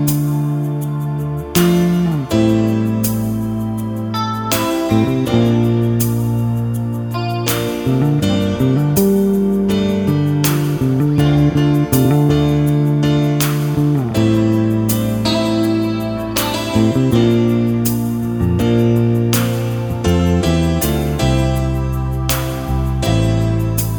no Backing Vocals Duets 6:01 Buy £1.50